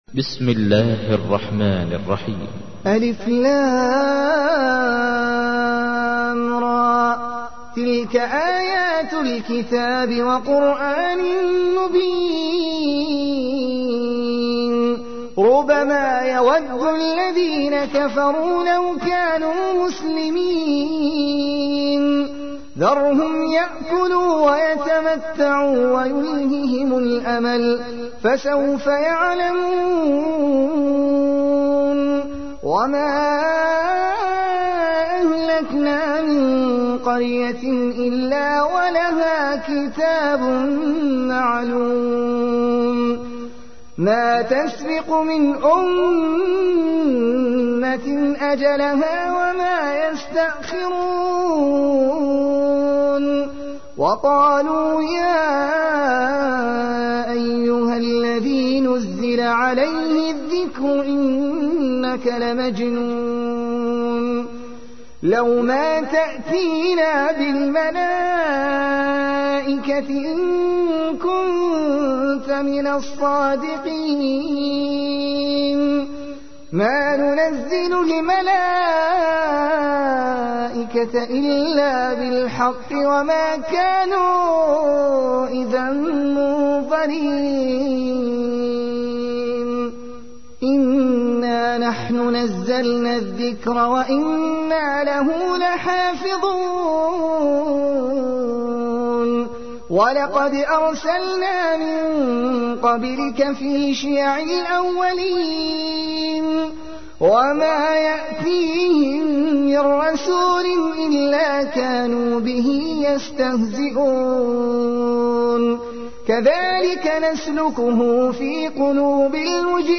تحميل : 15. سورة الحجر / القارئ احمد العجمي / القرآن الكريم / موقع يا حسين